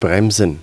bremsen.wav